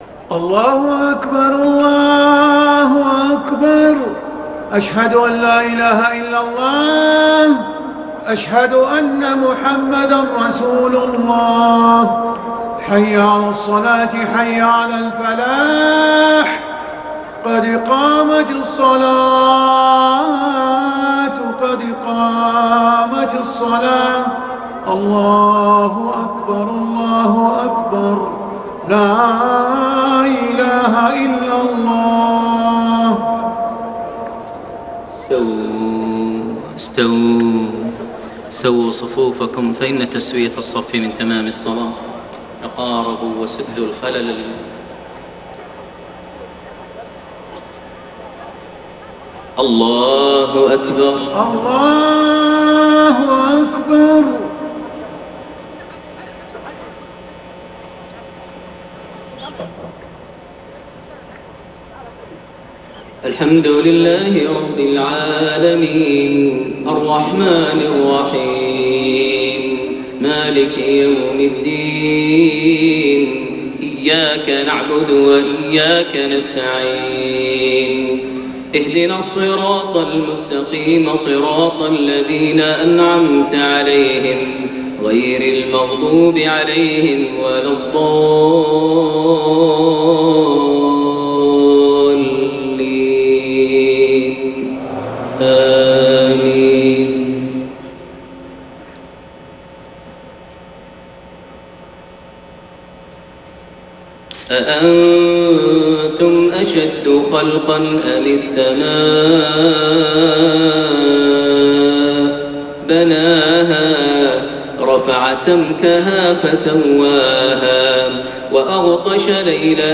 صلاة المغرب 6 شوال 1432هـ خواتيم سورة النازعات 27-46 > 1432 هـ > الفروض - تلاوات ماهر المعيقلي